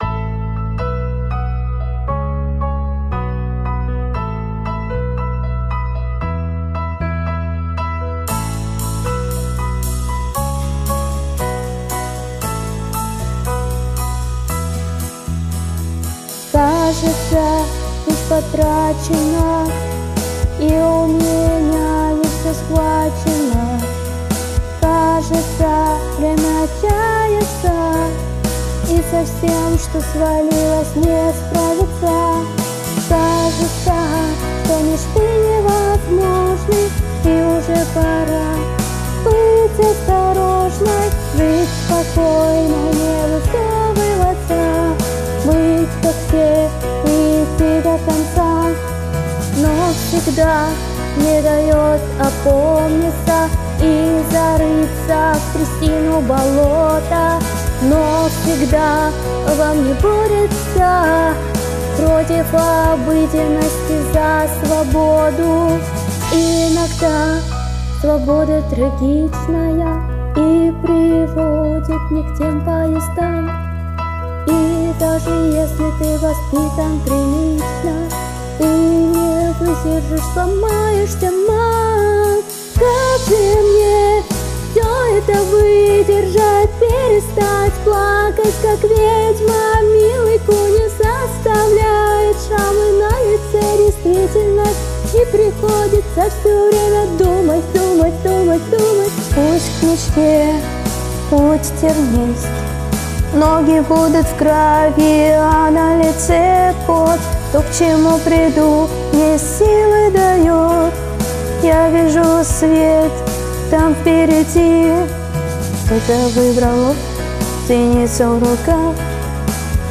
Я записала 2 разные версии песни (отличается музыка)